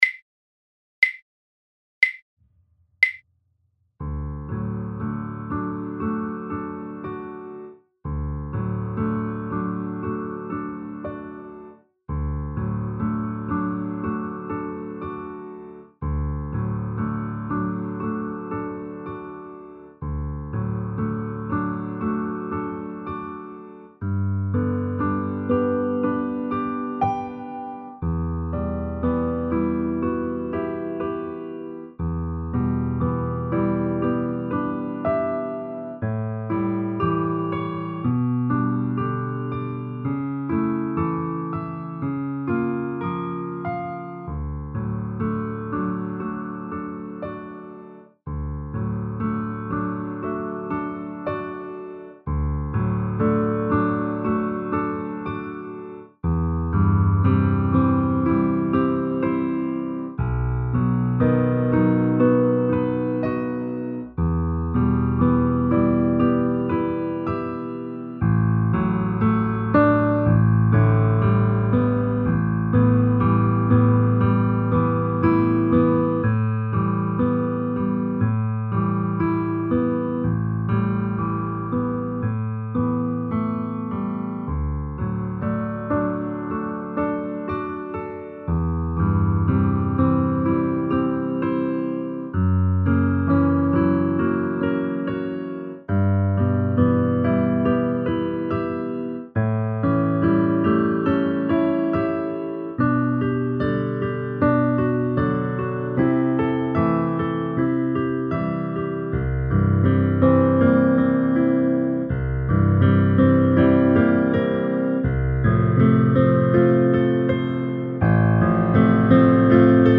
Sample backing track